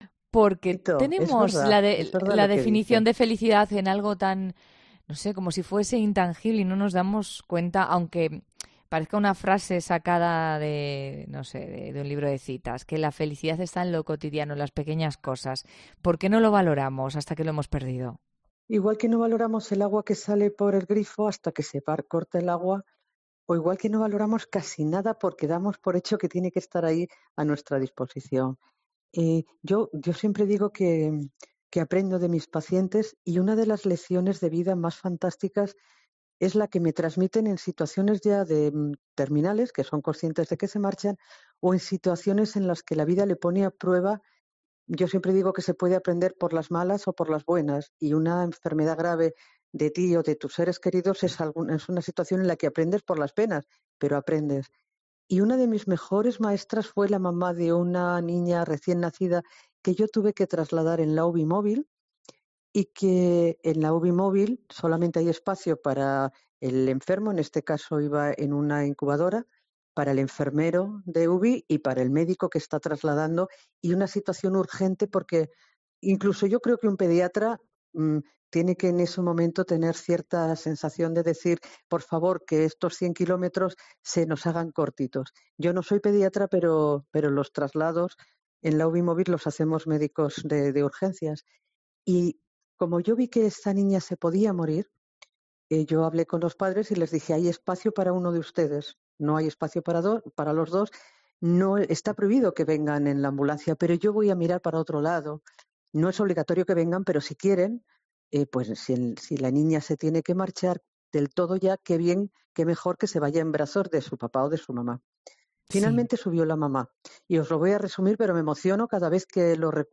La doctora explica en la entrevista que siempre aprende "de sus pacientes" y una de sus mejores maestras es "la mamá de una niña".